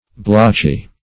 Blotchy \Blotch"y\, a.